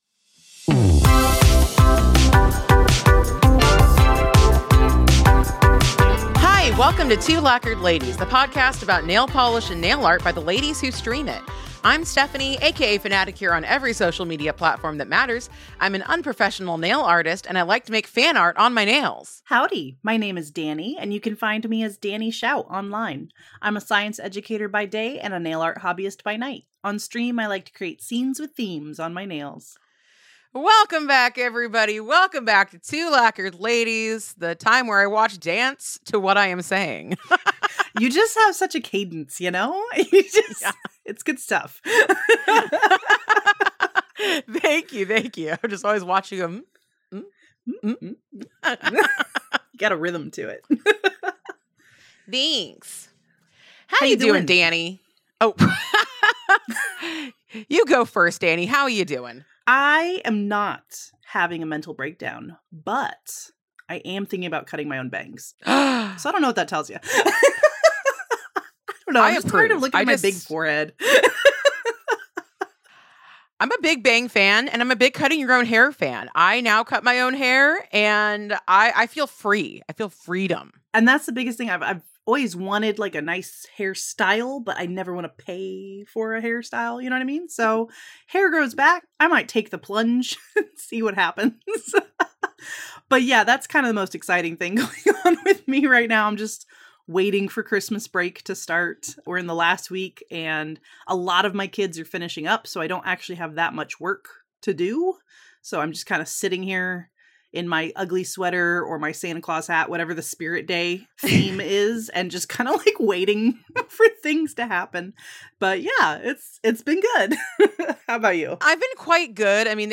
Two years passed since our last interview and so much has happened since then. So we're here to talk about it.